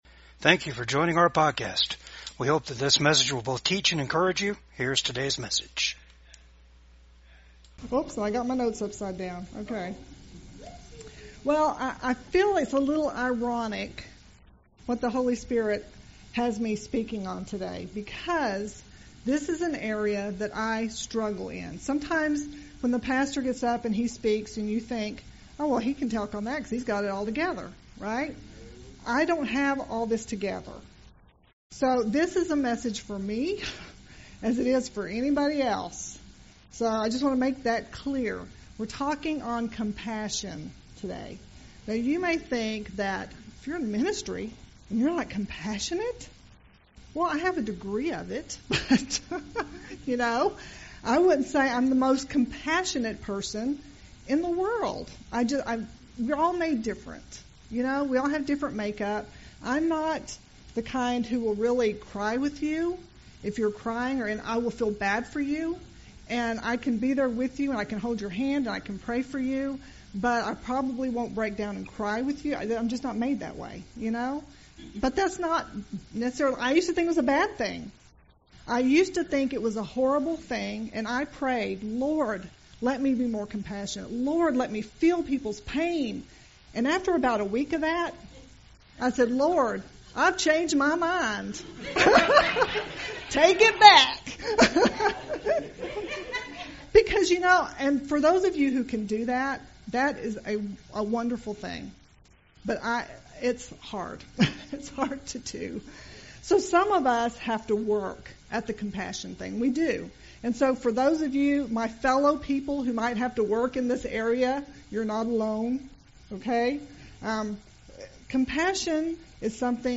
VCAG SUNDAY SERVICE